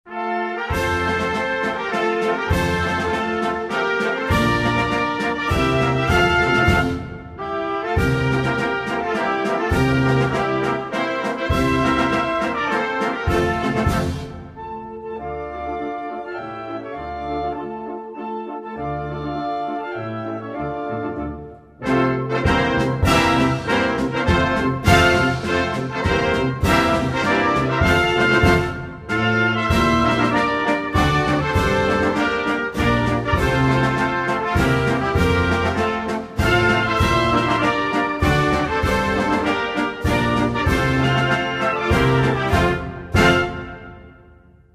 на трубе